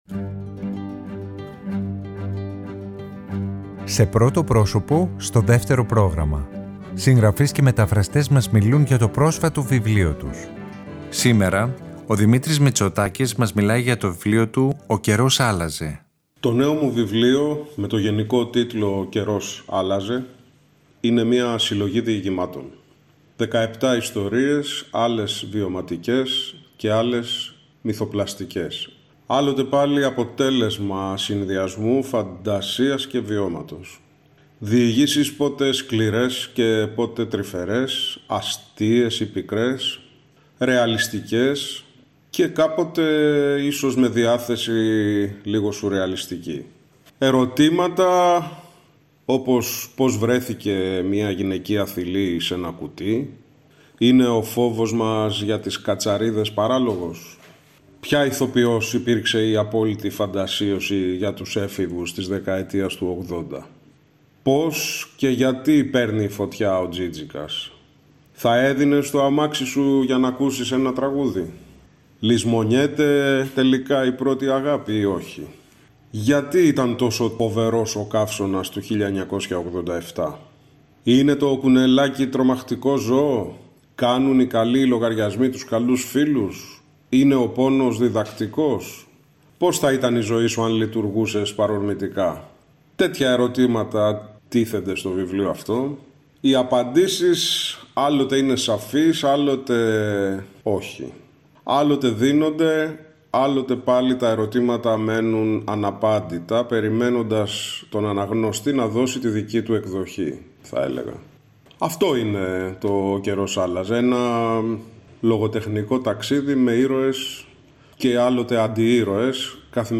Συγγραφείς και μεταφραστές μιλάνε